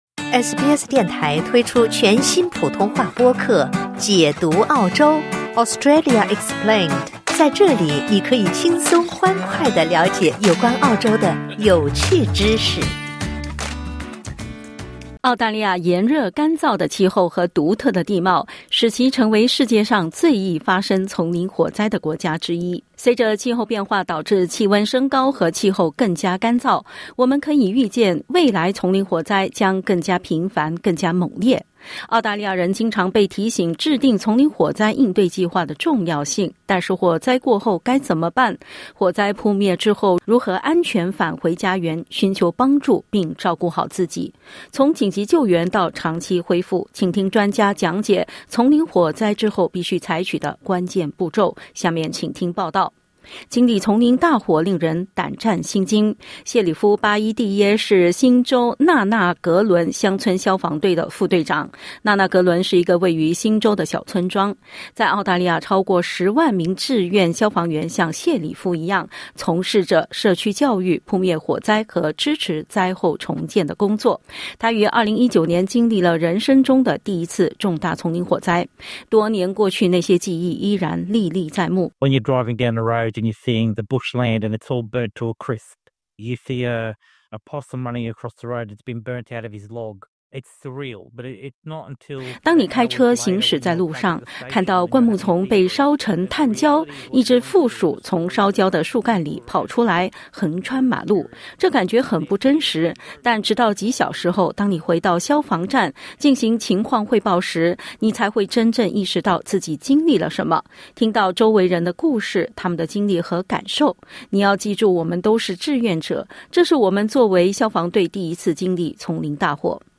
从紧急救援到长期恢复，请听专家讲解丛林火灾后必须采取的关键步骤。